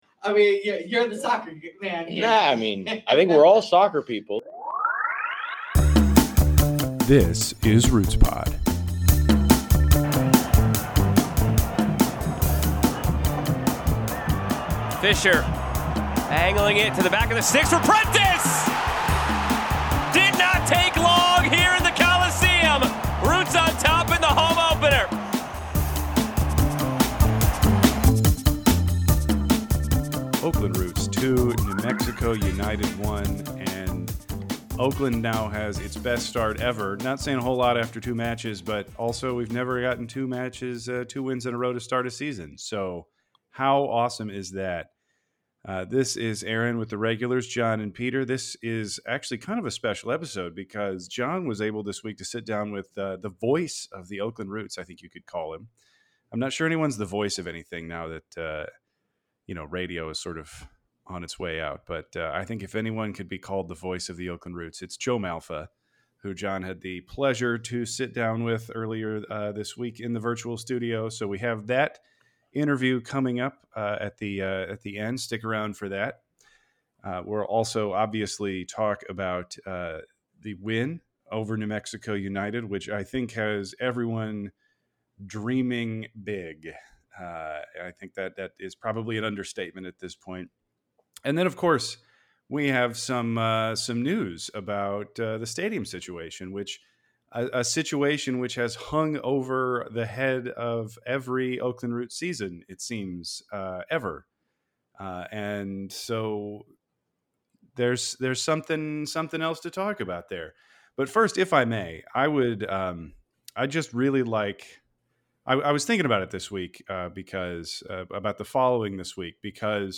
in a fantastically interesting interview.